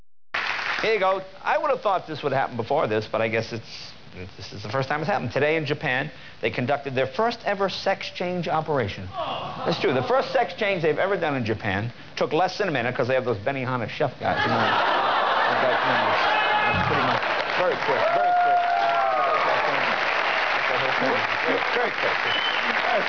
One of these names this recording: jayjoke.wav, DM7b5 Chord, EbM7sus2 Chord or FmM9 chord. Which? jayjoke.wav